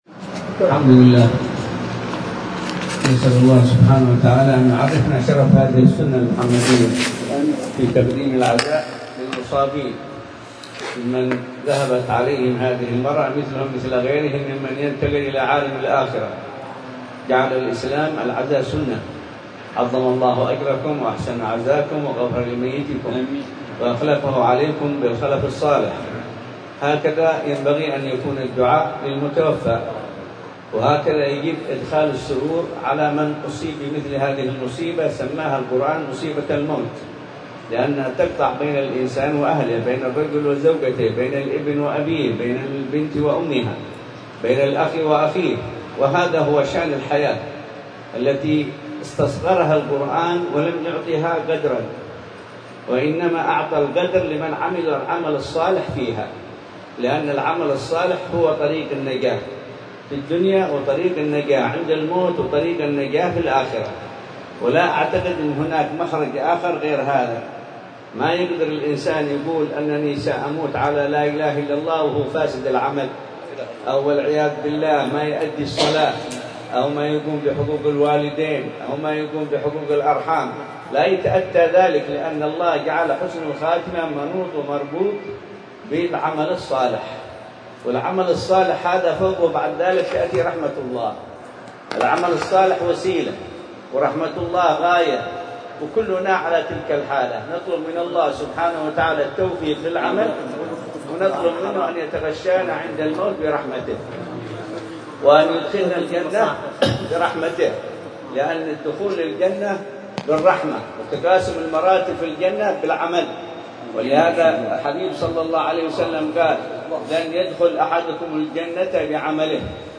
كلمة